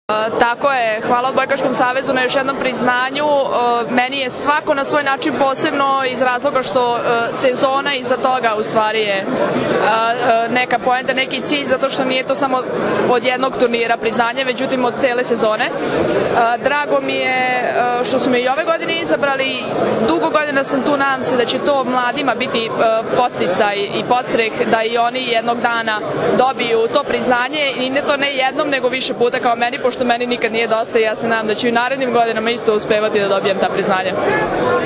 U beogradskom hotelu Metropol danas je održan tradicionalni Novogodišnji koktel Odbojkaškog saveza Srbije, na kojem su podeljenje nagrade najboljim pojedincima i trofeji “Odbojka spaja”.
IZJAVA